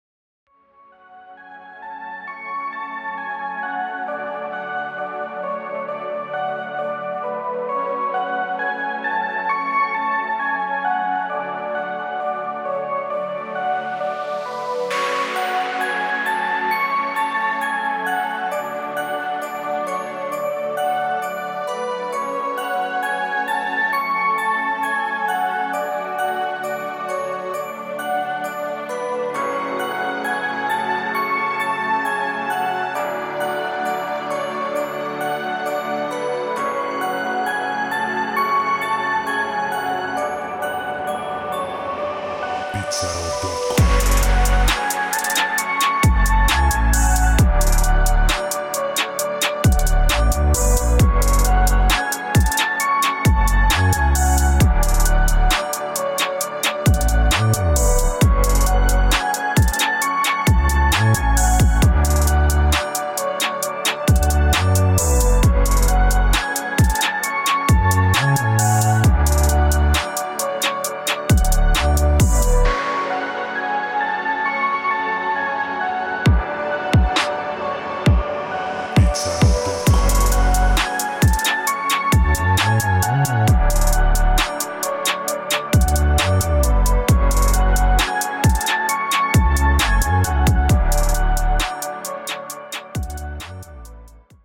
دسته و ژانر: ترپ
سبک و استایل: گنگ
سرعت و تمپو: 133 BPM